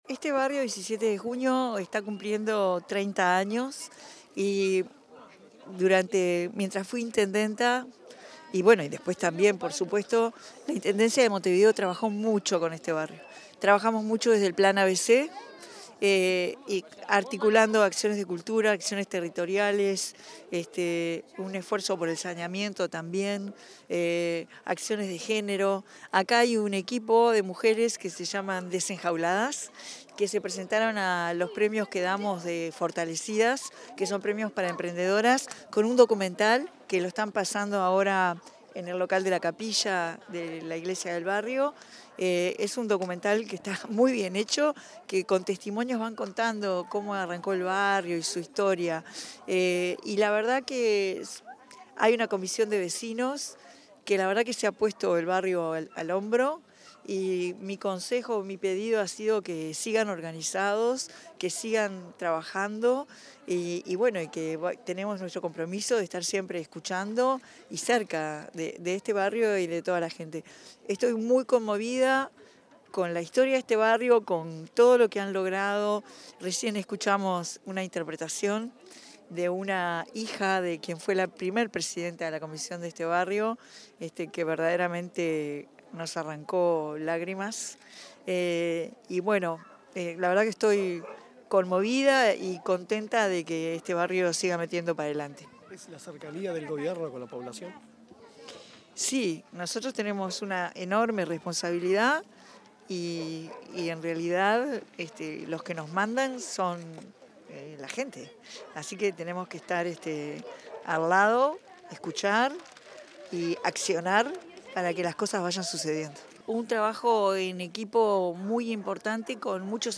Declaraciones de la presidenta de la República en ejercicio, Carolina Cosse
Declaraciones de la presidenta de la República en ejercicio, Carolina Cosse 28/06/2025 Compartir Facebook X Copiar enlace WhatsApp LinkedIn Con motivo del 30.° aniversario de la comisión barrial 17 de Junio, en Montevideo, la presidenta de la República en ejercicio, Carolina Cosse, acompañó la celebración y realizó declaraciones.